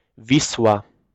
The Vistula (/ˈvɪstjʊlə/; Polish: Wisła [ˈviswa]